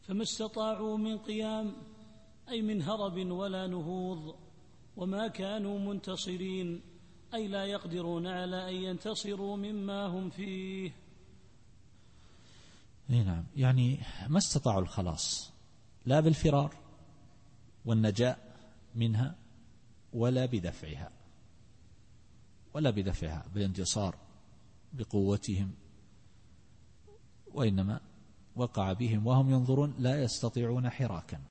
التفسير الصوتي [الذاريات / 45]